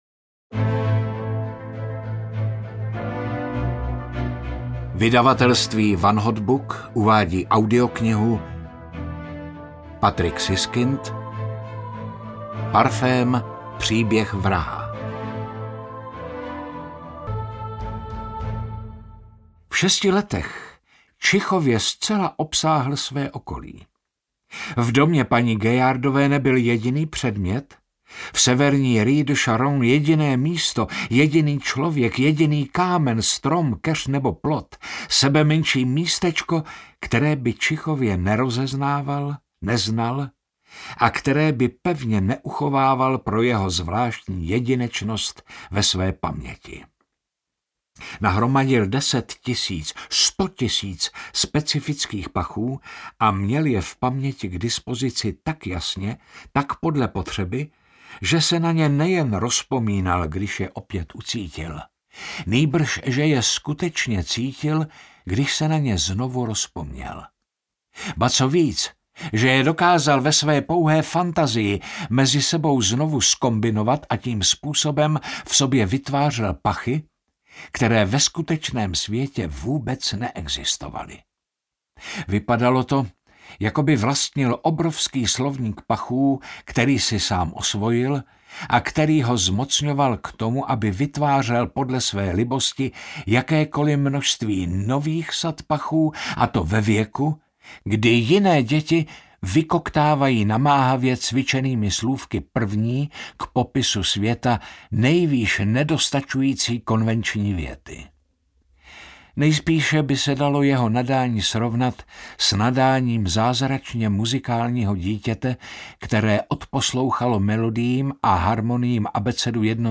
Parfém - příběh vraha audiokniha
Ukázka z knihy